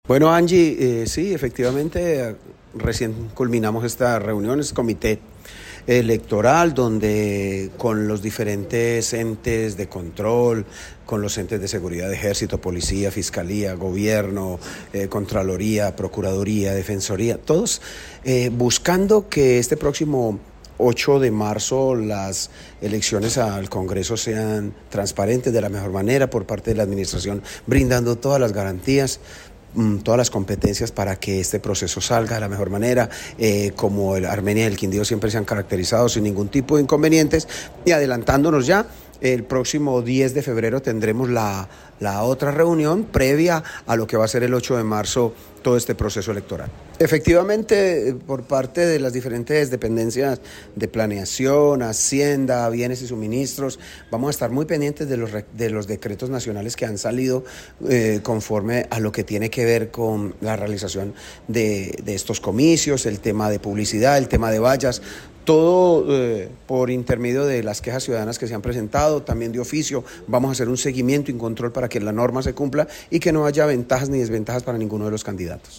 Alcalde de Armenia, James Padilla García, sobre seguimiento electoral 2026